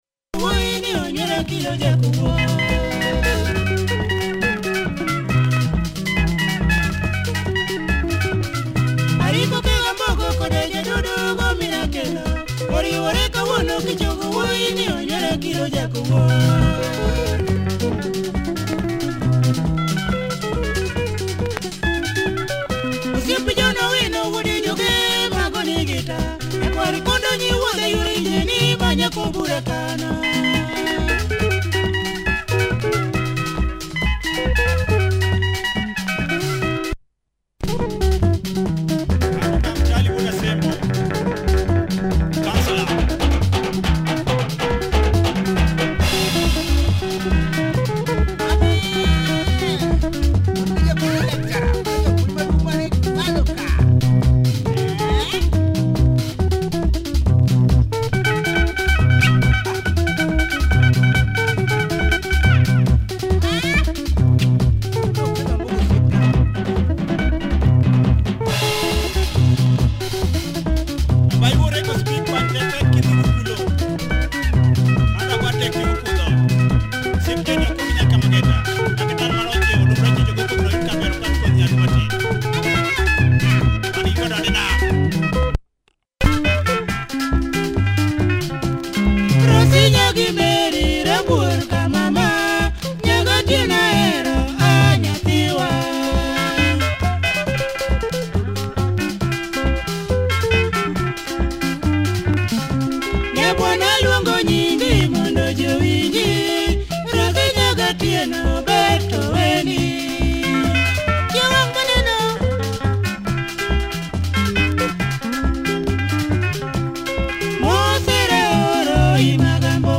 LUO benga